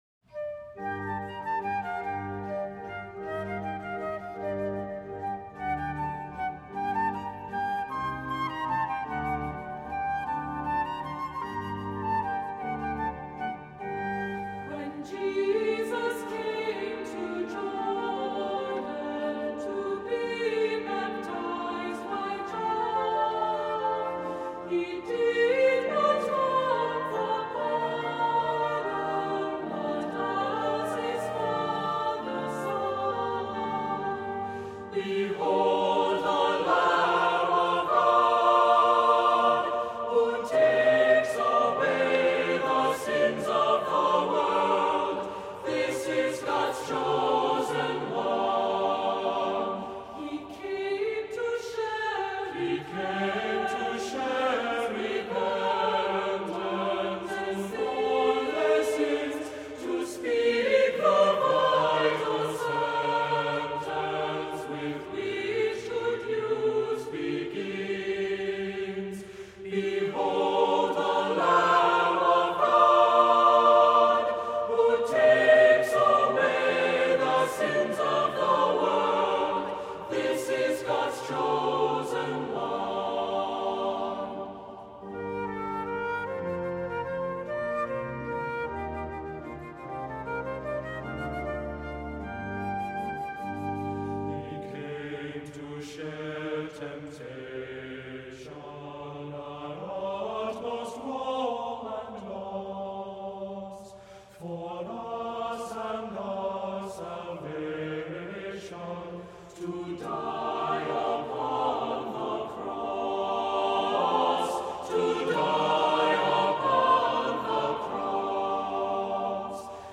Adult Choir at Holy Trinity Lutheran Church in Ankeny, IA
Here is a recording of our choir singing